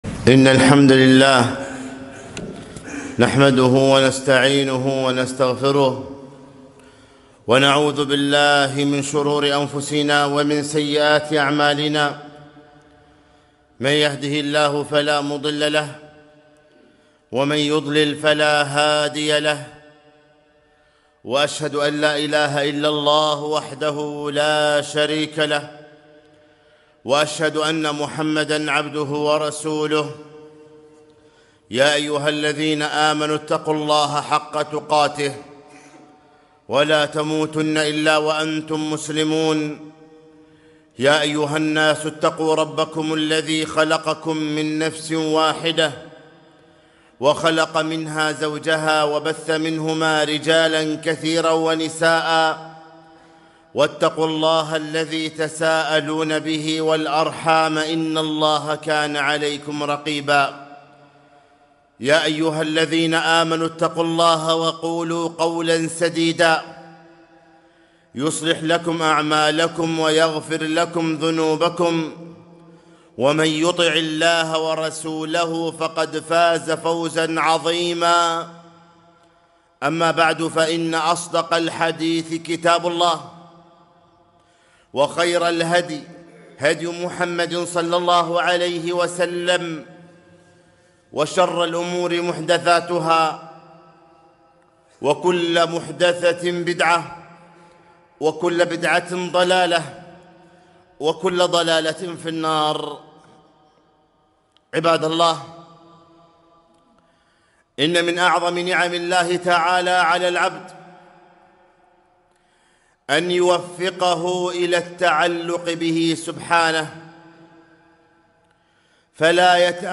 خطبة - تعلق القلب بغير الله تعالى